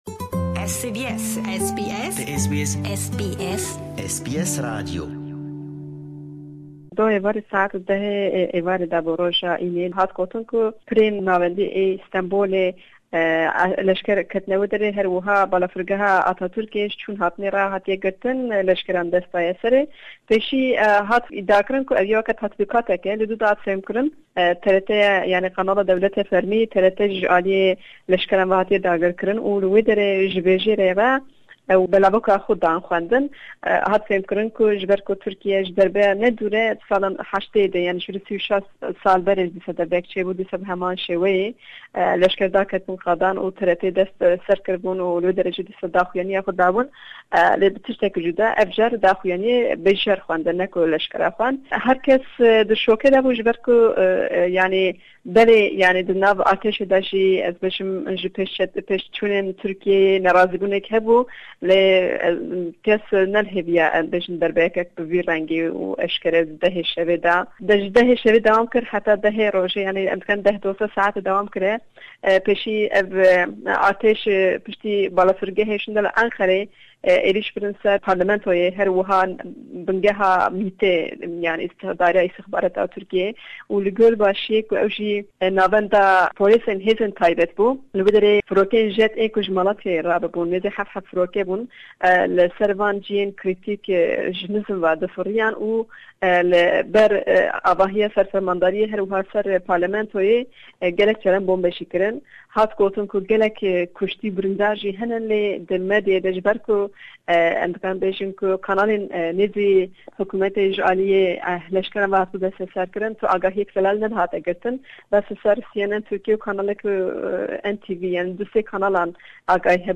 raporteke taybet sebaret bi hewildana derberya leshkerî li Turkiyê ji Diyarbekir pêshkêsh dike.